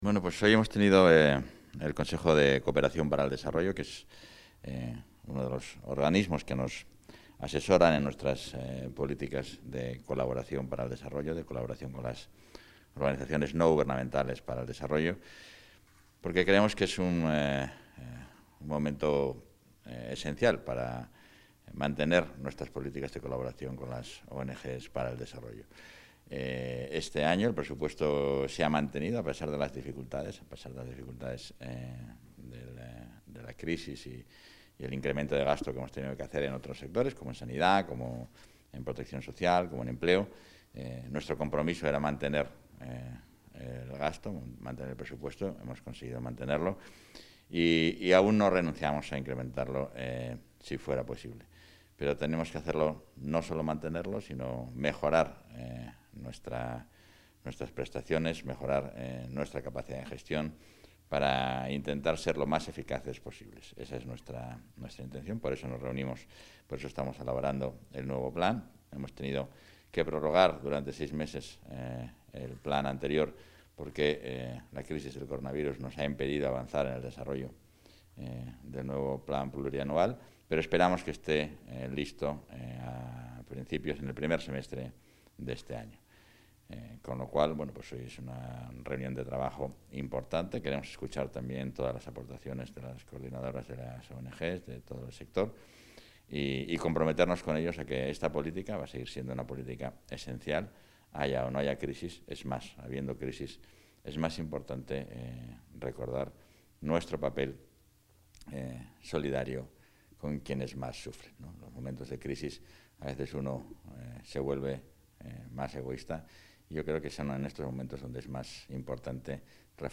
Valoración del vicepresidente y portavoz.